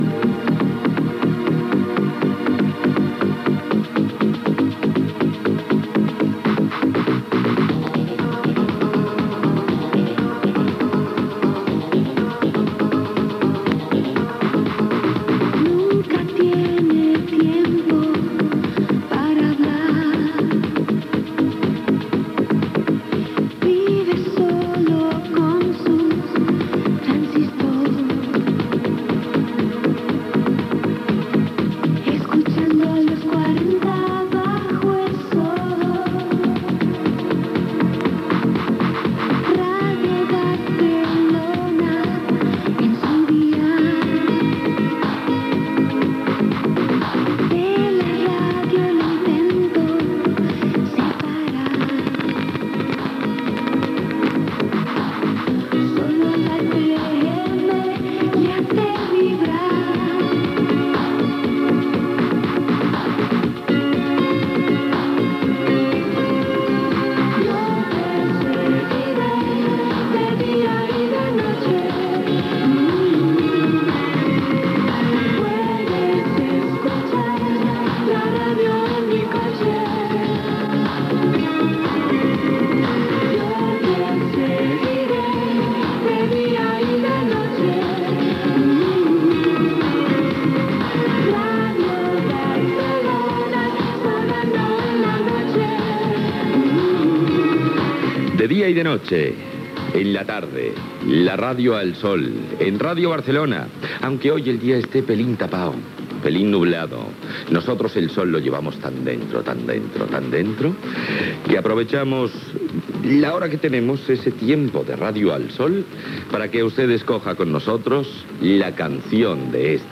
Cançó de Ràdio Barcelona "Los 40 bajo el sol", estat del temps, publicitat, la "Canción del verano", telèfons de participació
Entreteniment